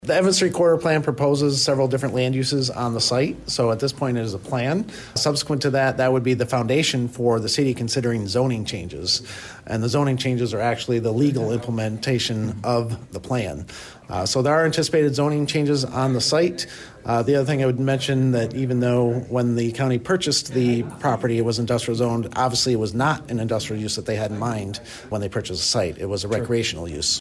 Tecumseh City Manager Dan Swallow told WLEN News that the plan would set the City up for possible zoning changes.